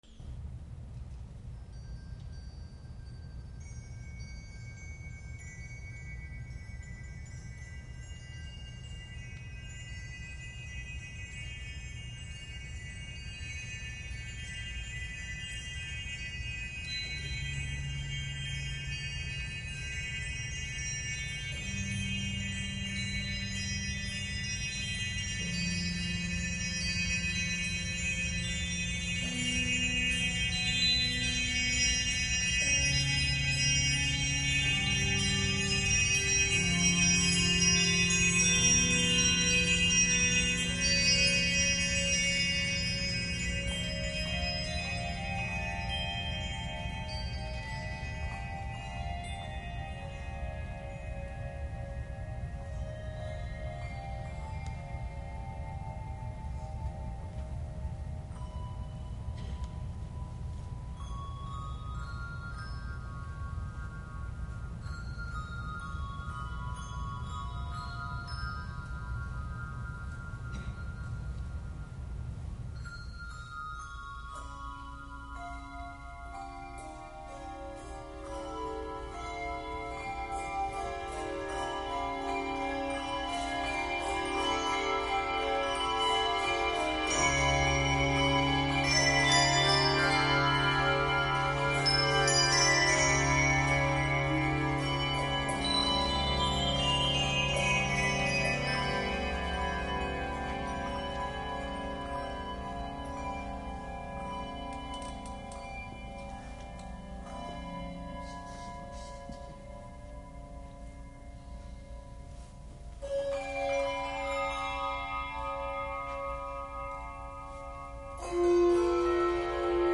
Voicing: Handbells 5-7 Octave